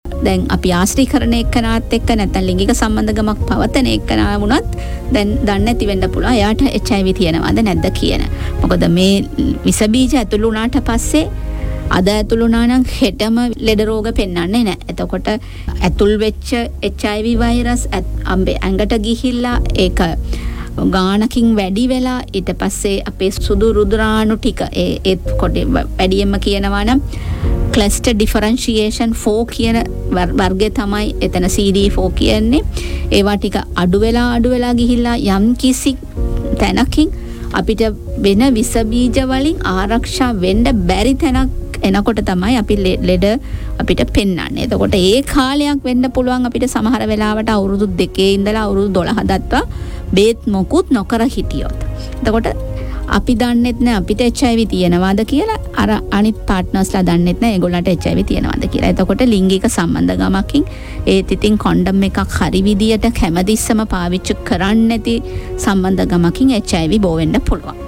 ඇය මෙම අදහස් දැක්විම සිදු කළේ අද දින වී එෆ්එම් කතා මිනිස්ට්‍රි කාලීන වැඩසටහනට එක් වෙමින්.